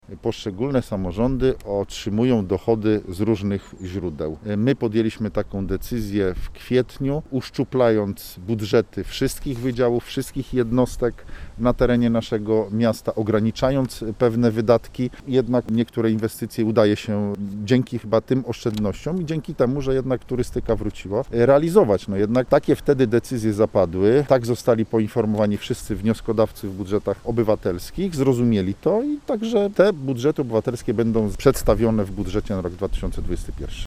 Mówi burmistrz Sandomierza Marcin Marzec: